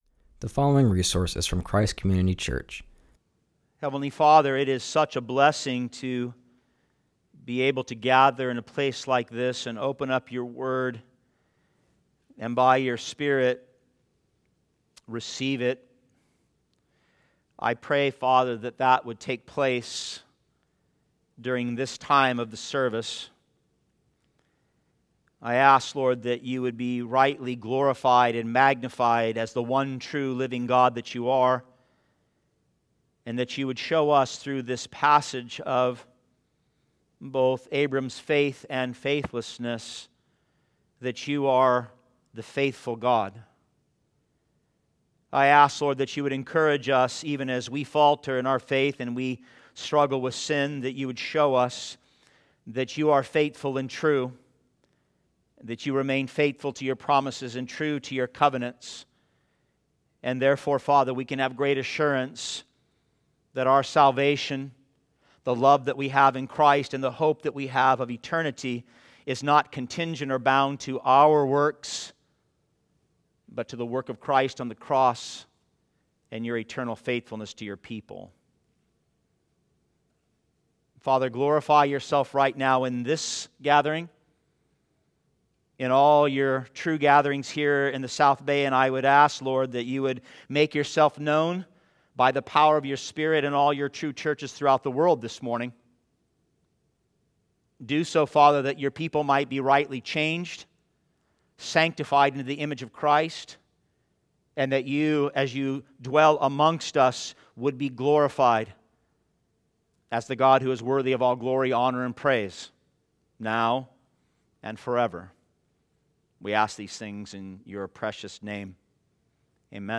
continues our series and preaches from Genesis 12:4-13:2.